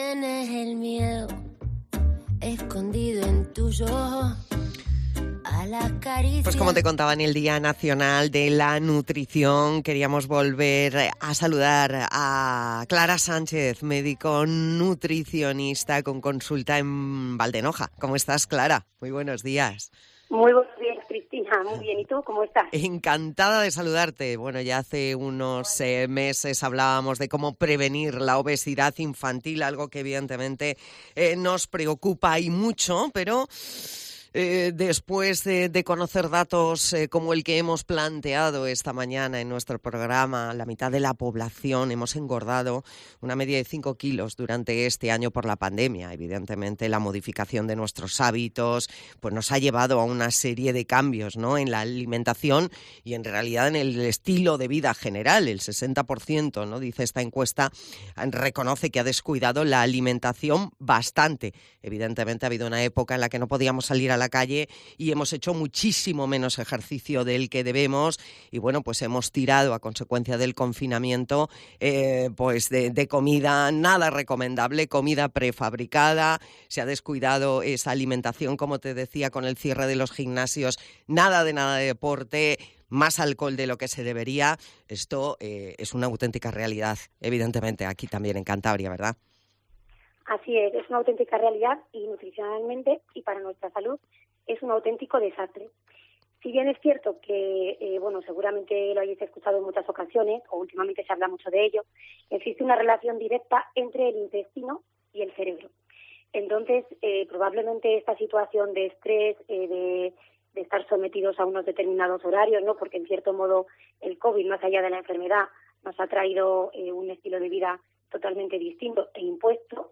Escucha aquí la entrevista entera en Mediodia Cope Cantabria.